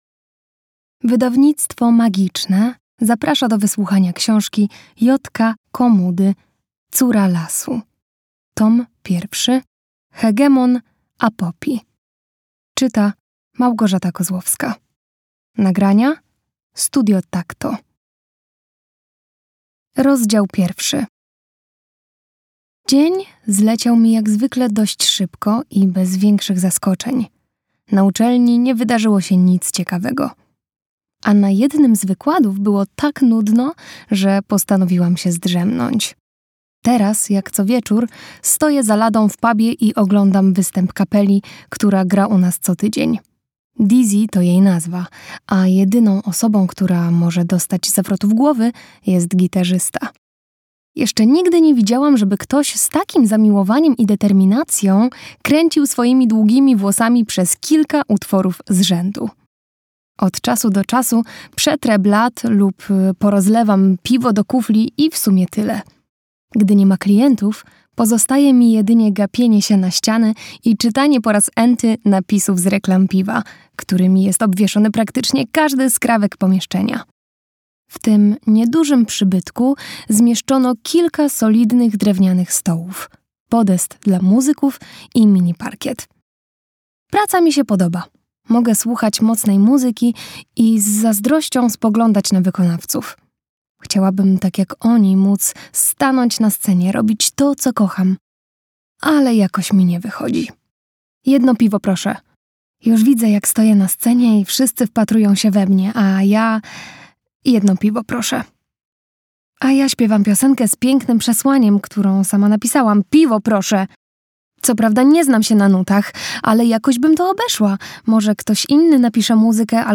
Hegemon Apopi. Córa Lasu. Tom 1 - J. K. Komuda - audiobook